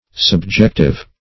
Subjective \Sub*jec"tive\, a. [L. subjectivus: cf. F.